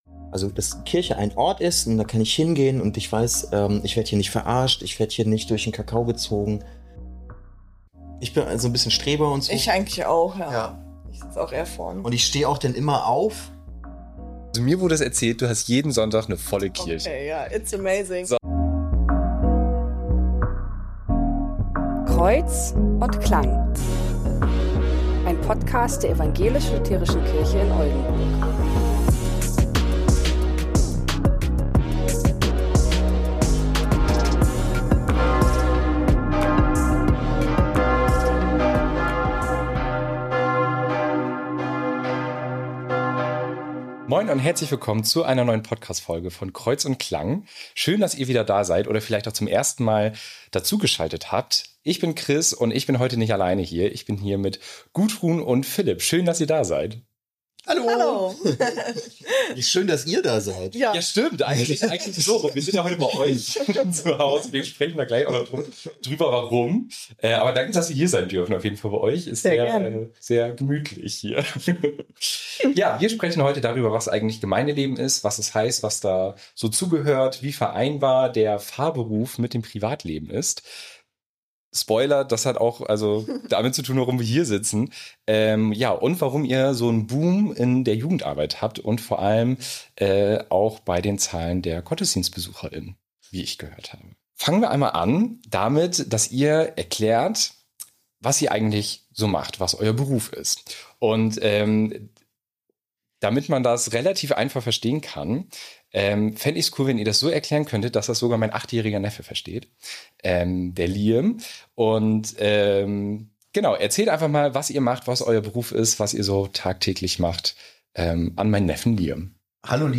Für die Audioaufnahme der Kirchenglocken der St. Secundus Kirche in Schwei im Intro